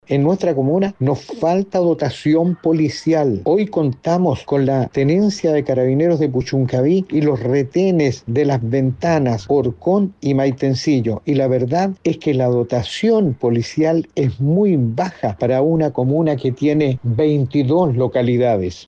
En tanto, el concejal Juan Elías Pérez criticó la falta de dotación policial, que —según señaló— es muy baja para una comuna que cuenta con 22 localidades.